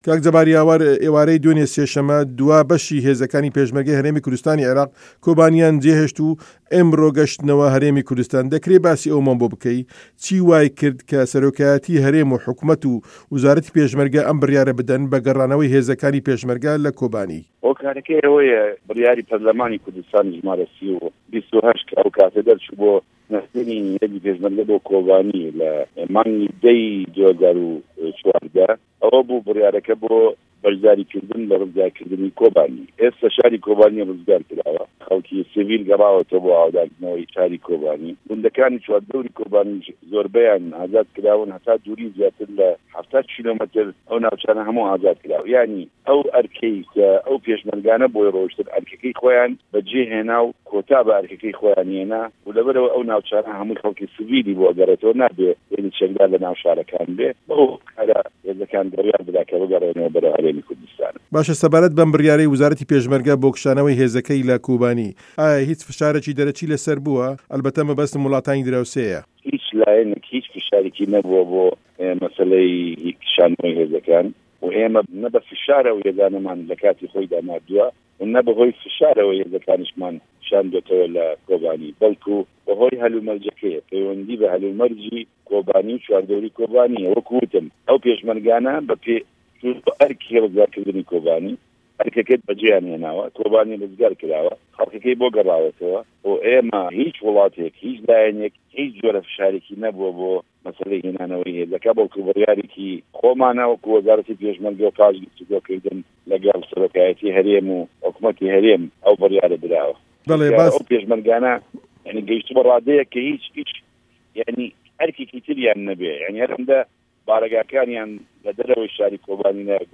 وتووێژی جه‌بار یاوه‌ر